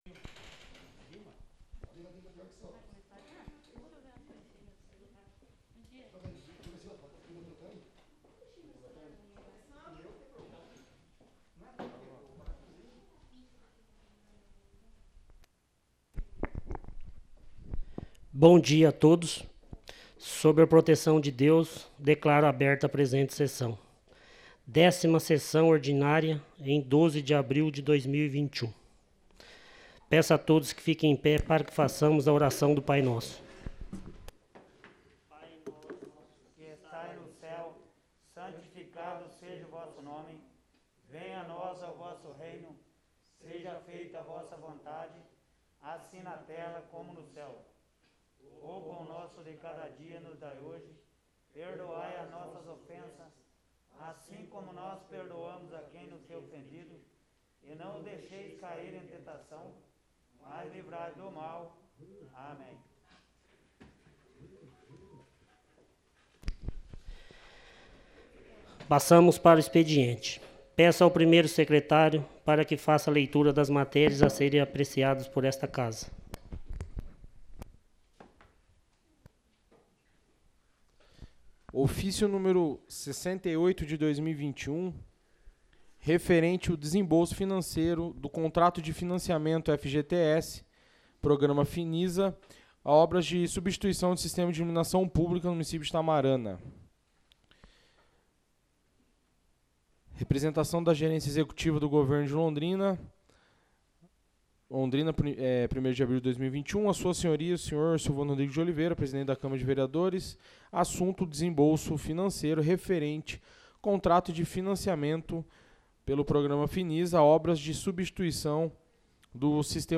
10ª Sessão Ordinária